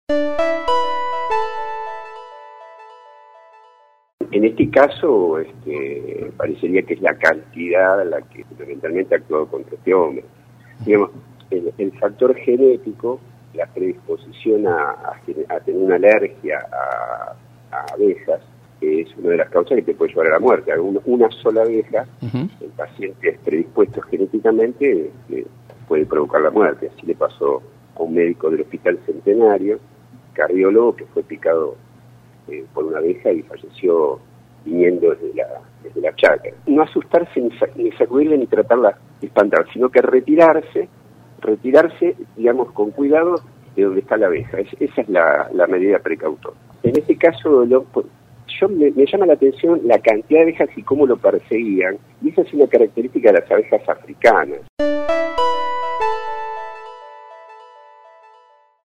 En diálogo con el programa La barra de Casal